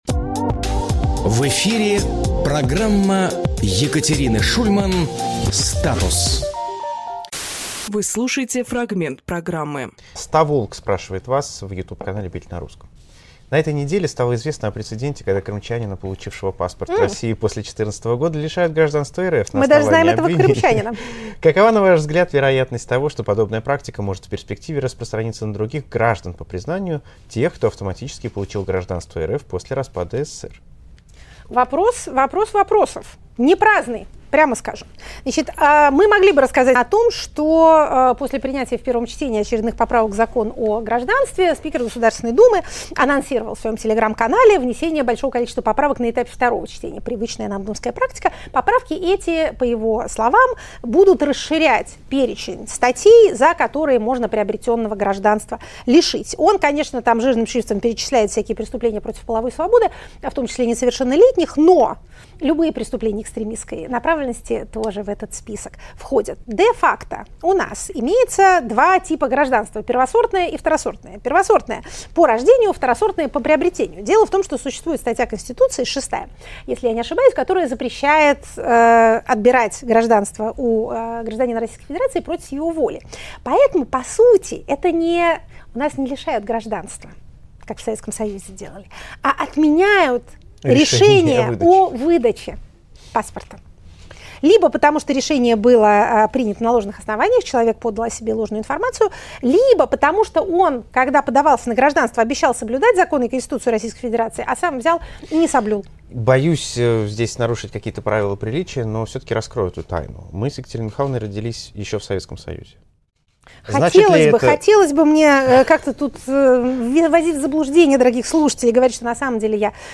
Екатерина Шульманполитолог
Фрагмент эфира от 15.07.25